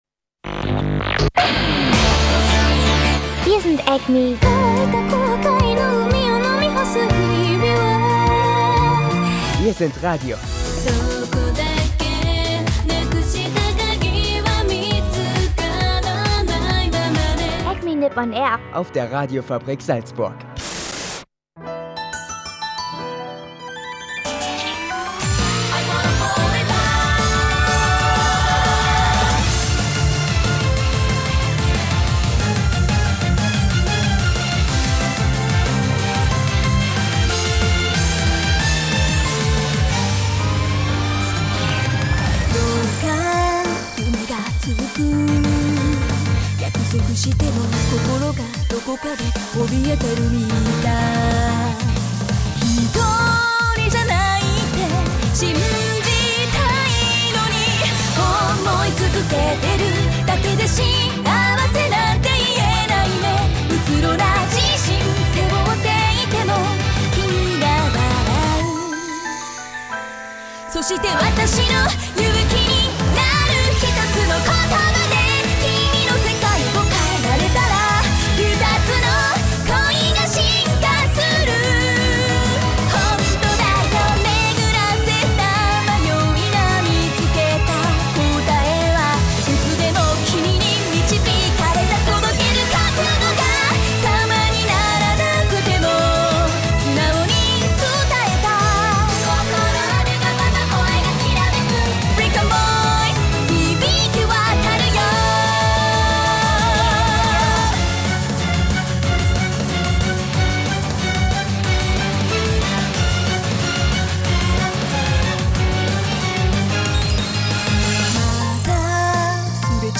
Dazu gibts natürlich Anime-Musik, Japan-News und -Wetter.